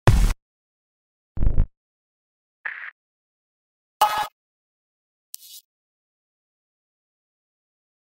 Um das Transformationspotenzial zu verdeutlichen, hier ein Single-Sound (solche sind reichlich in Glitch Art vorhanden) mit vier Variationen: